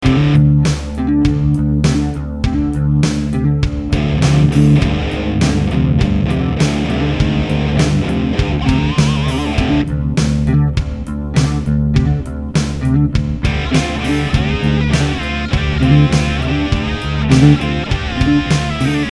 Verse
Here’s what both the guitar and bass sound like together: